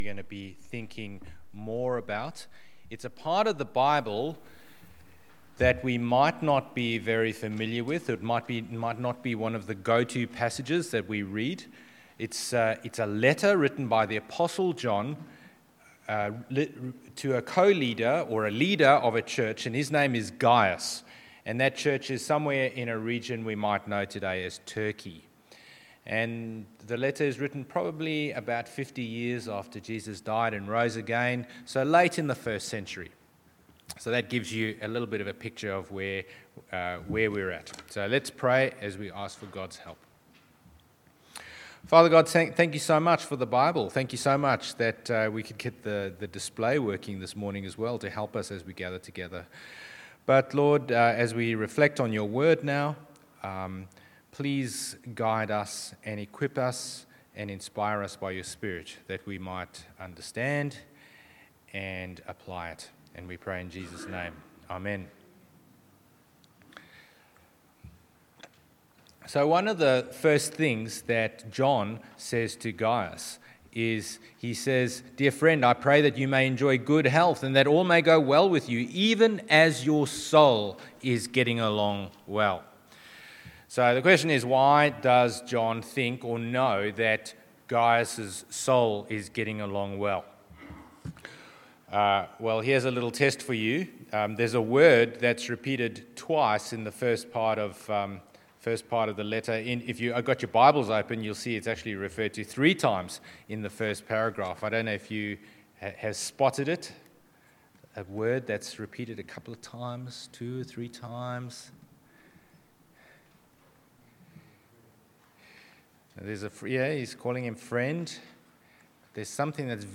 Passage: 1 John 1:1-10 Service Type: Sunday morning service